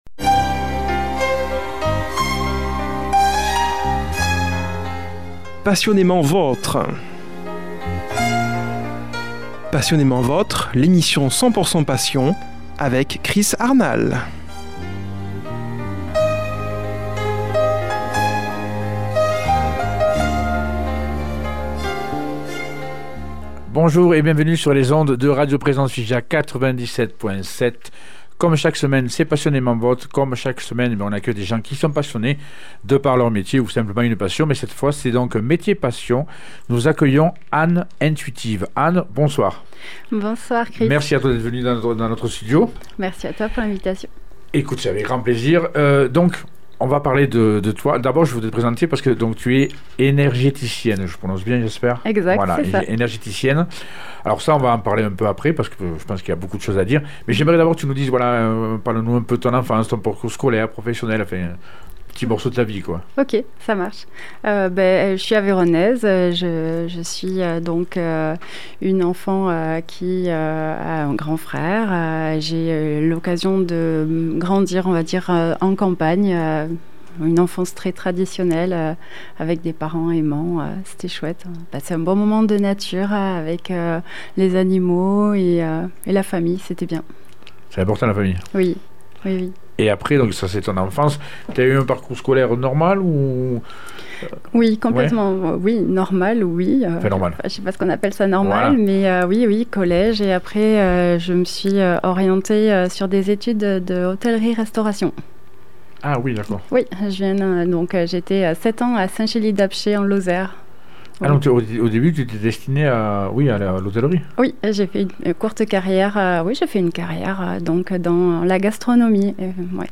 reçoit au studio comme invitée